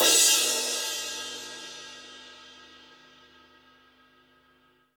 Index of /90_sSampleCDs/Roland L-CD701/CYM_Crashes 1/CYM_Crash menu